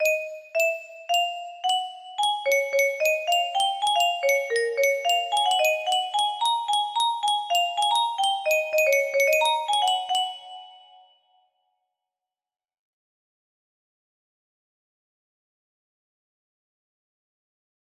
etoile music box melody